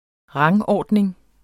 Udtale [ ˈʁɑŋˌɒˀdneŋ ]